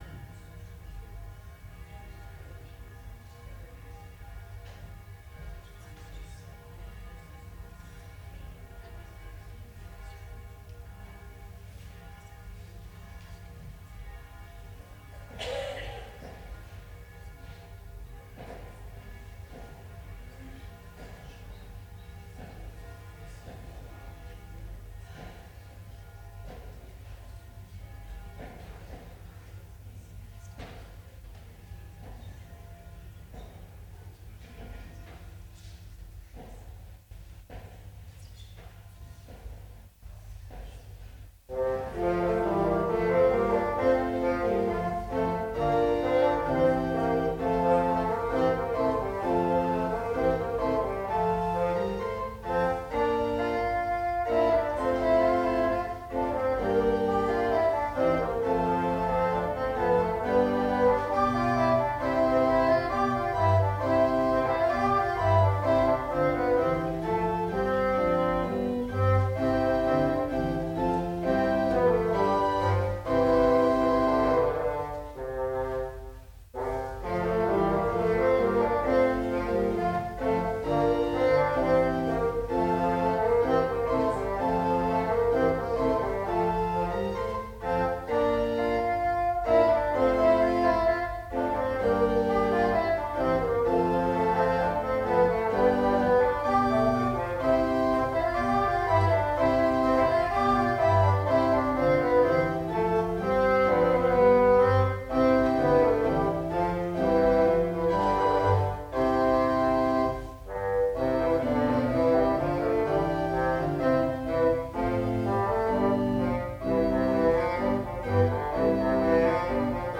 Gottesdienst - 31.12.2025 ~ Peter und Paul Gottesdienst-Podcast Podcast